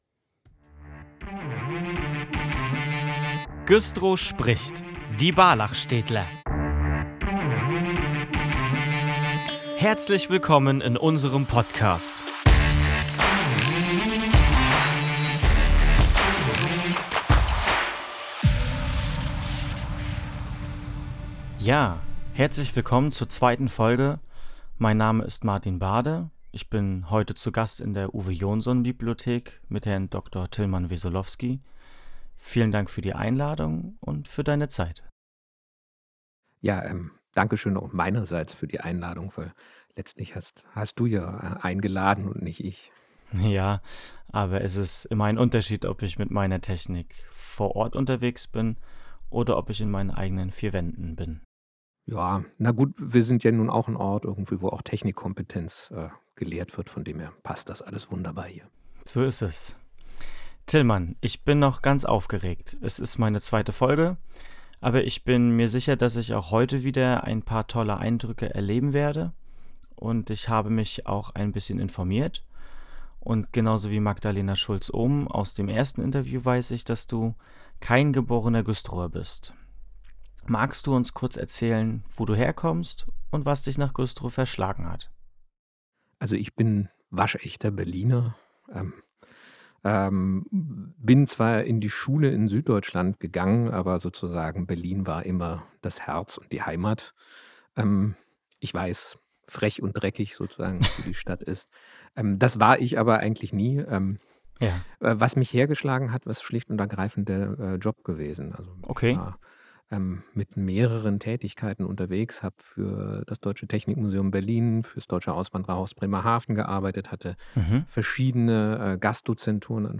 Folge 002 - Im Gespräch